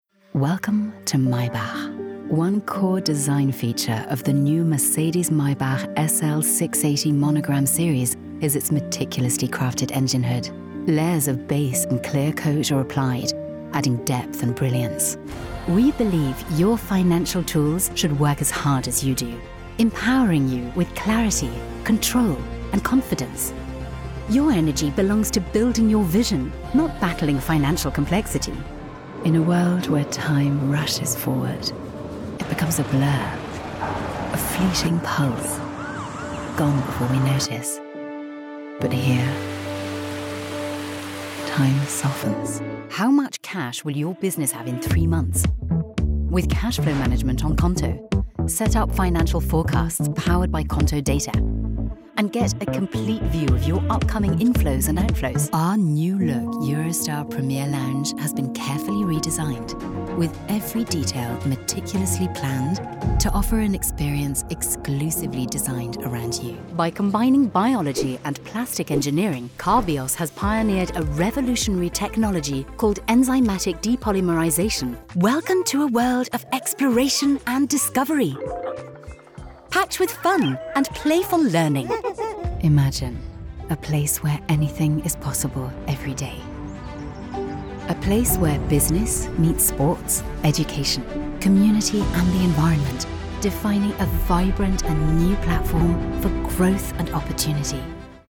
Inglés (Británico)
Cálida, Llamativo, Versátil, Seguro, Natural
Corporativo